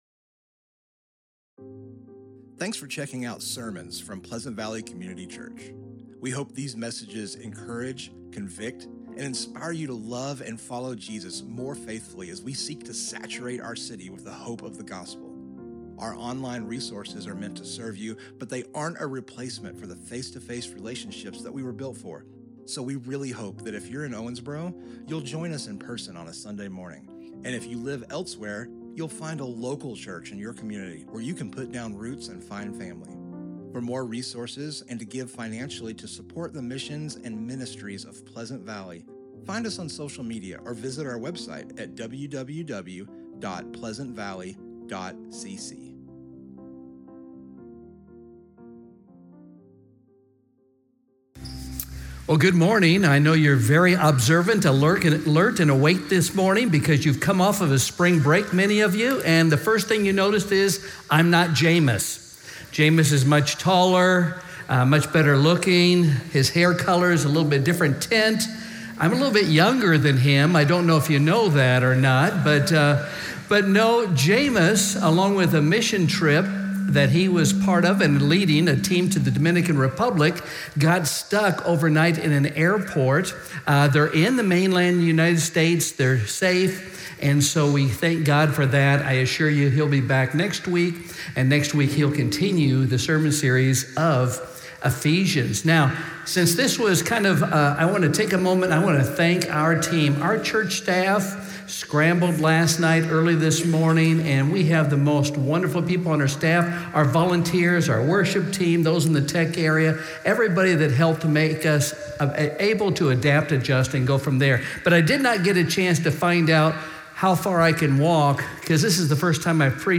Sermons Archive - Pleasant Valley Community Church